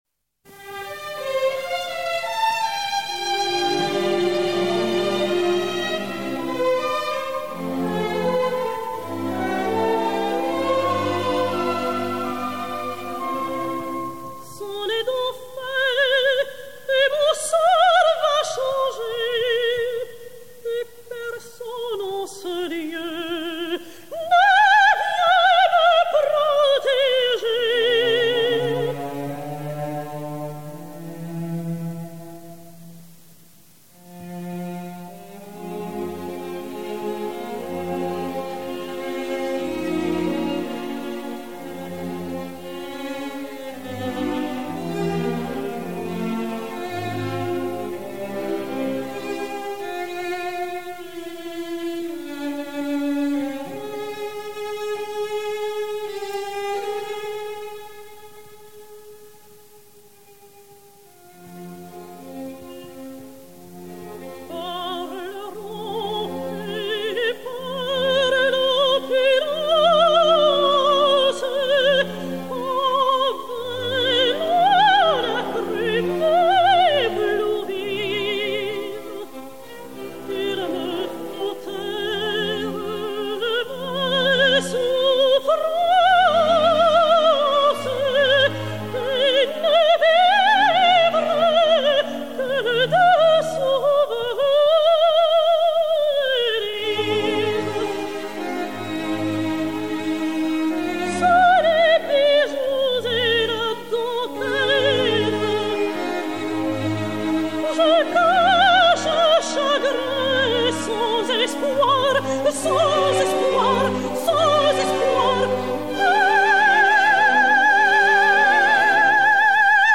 Air et chœur "Salut à la France"
Renée Doria (Marie), Chœurs et Orchestre